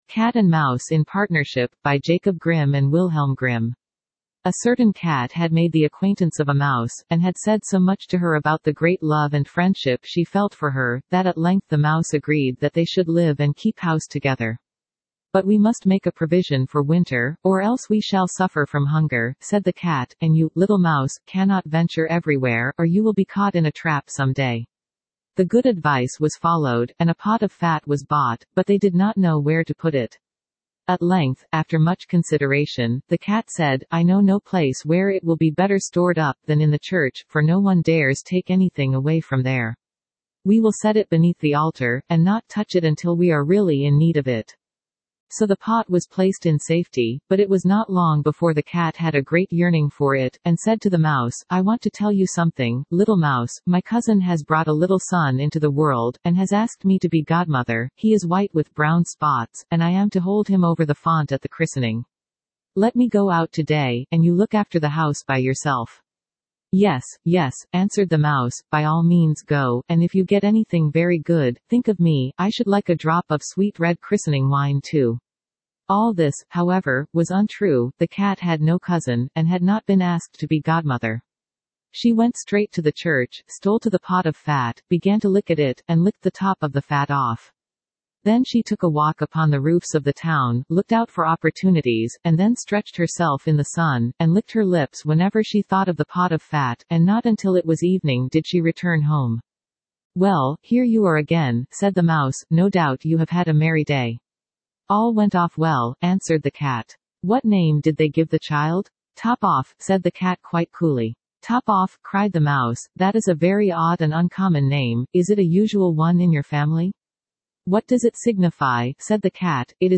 Standard (Female)